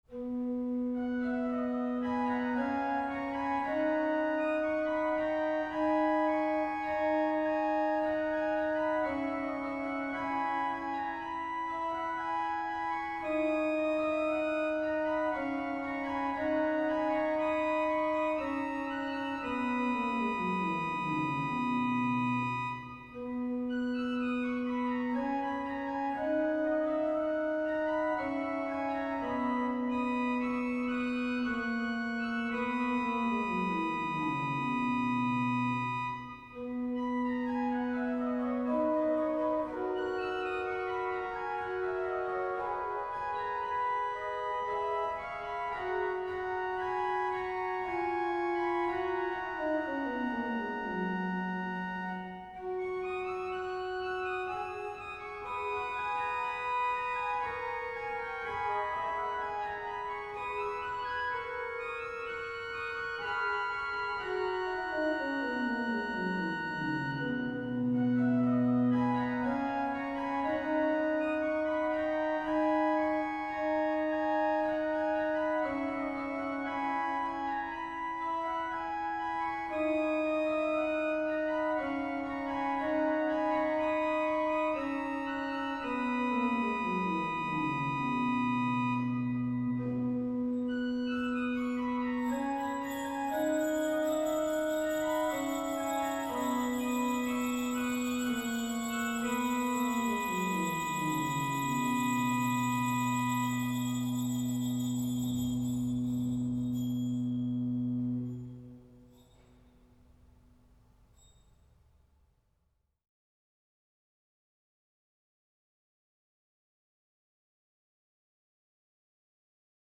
Organist